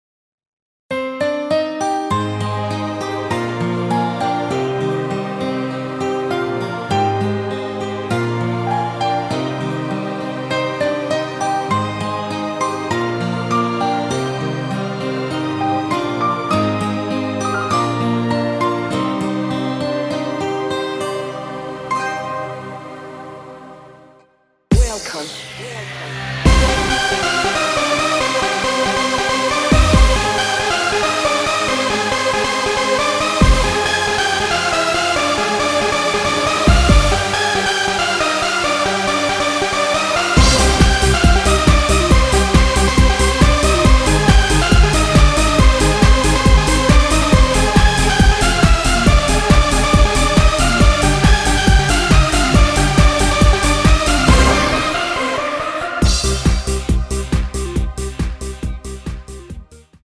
Cm
앞부분30초, 뒷부분30초씩 편집해서 올려 드리고 있습니다.
중간에 음이 끈어지고 다시 나오는 이유는